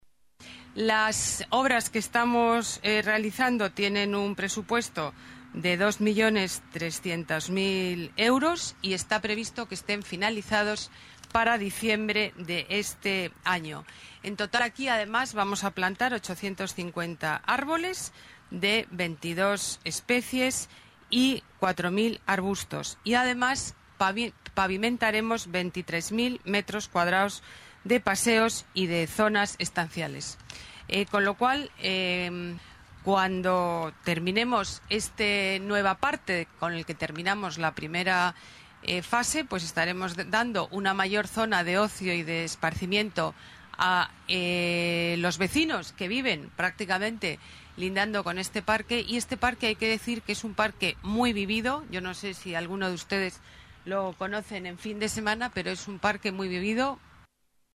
Nueva ventana:Declaraciones de Ana Botella, delegada de Medio Ambiente, sobre el Parque Lineal del Manzanares